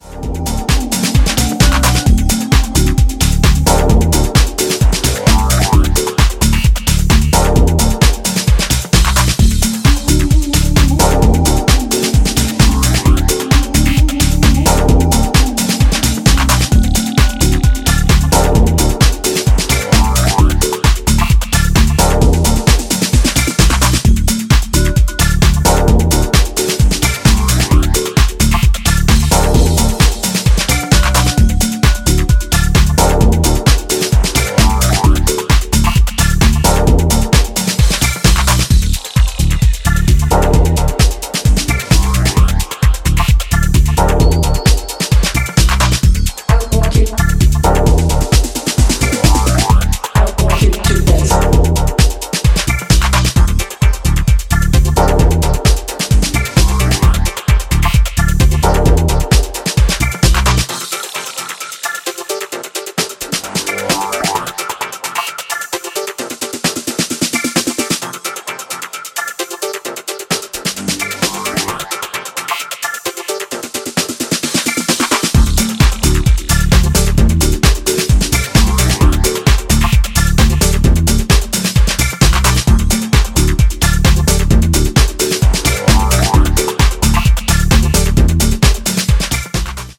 幽玄なコズミック・シンセと共に疾走する
推進力抜群のエネルギーとサイケデリックなテクスチャーが共存した精彩なプロダクションが光ります。